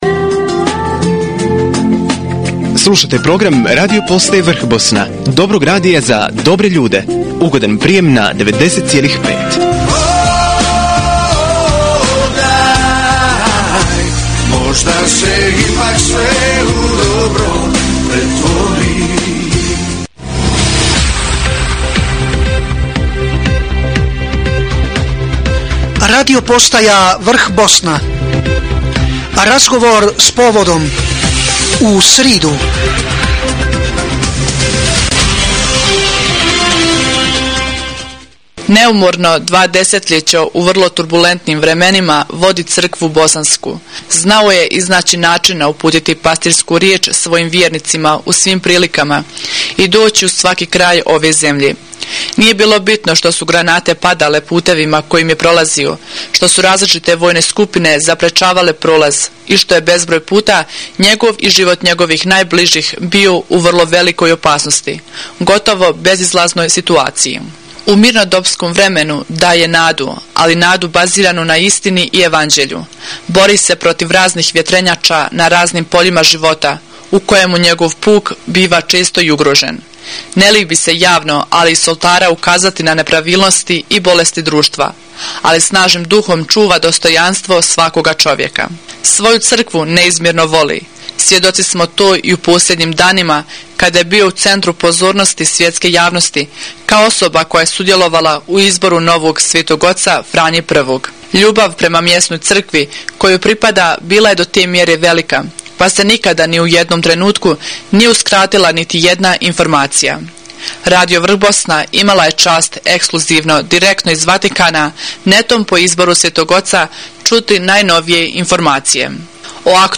AUDIO: Intervju kardinala Puljića radiopostaji Vrhbosna
Nadbiskup metropolit vrhbosanski kardinal Vinko Puljić, 25. ožujka 2013. bio je gost na Radio postaji Vrhbosna u Sarajevu u emisiji pod naslovom: Razgovor s povodom – u sridu.